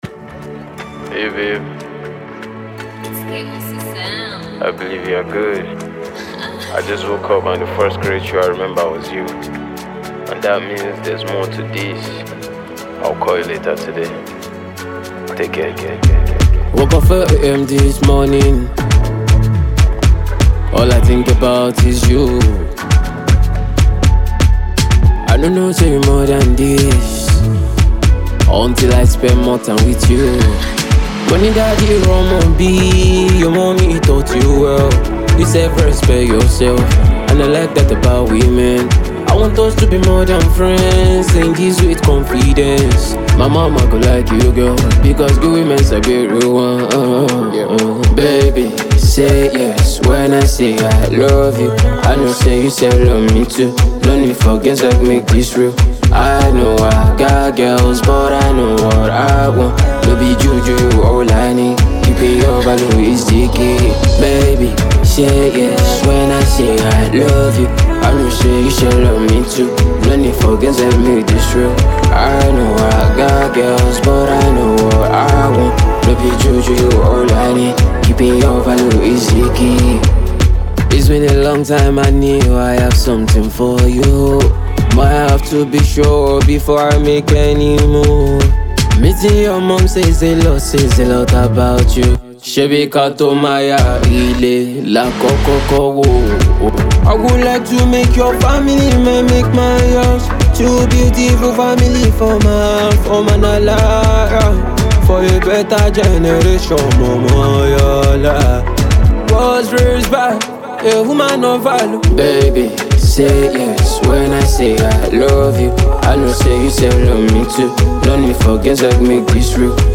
Afro-fusion, Afrobeat, Hip-Hop